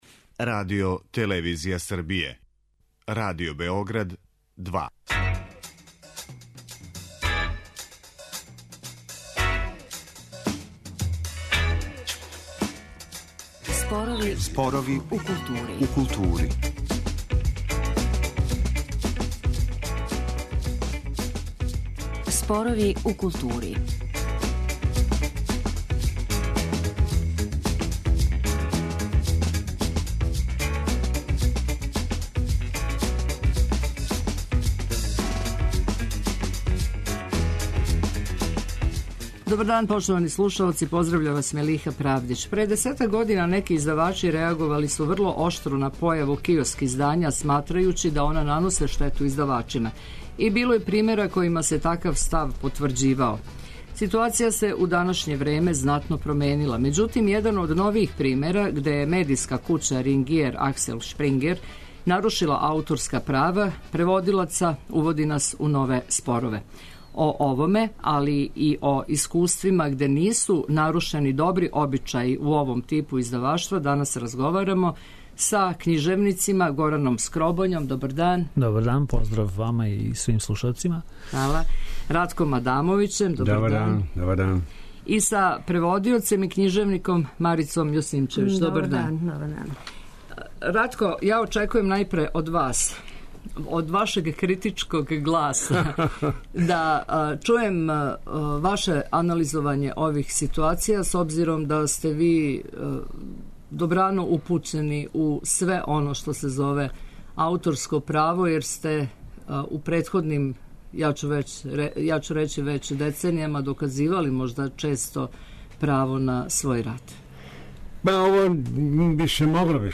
разговара са књижевницима